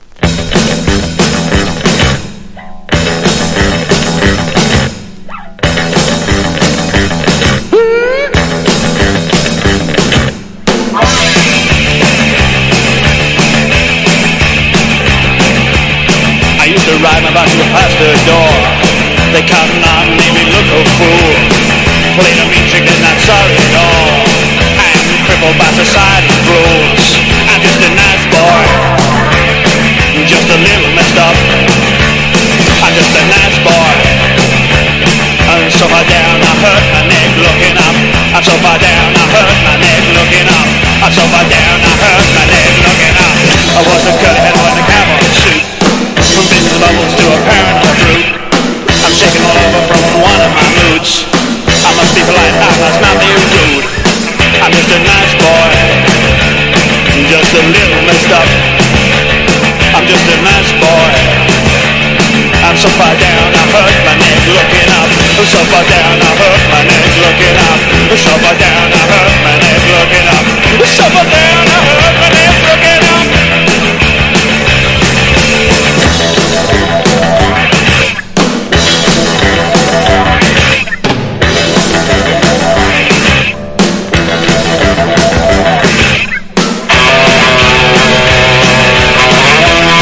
AMBIENT / EXPERIMENTAL# NEW WAVE# POST PUNK# 80’s ROCK